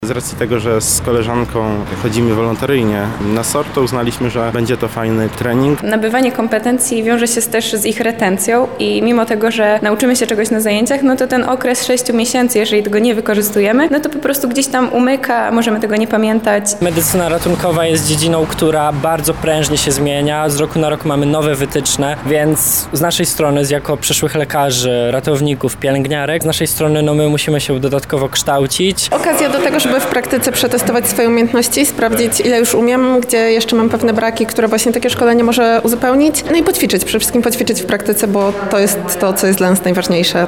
Zapytaliśmy studentów Uniwersytetu Medycznego w Lublinie o powód ich uczestnictwa:
sonda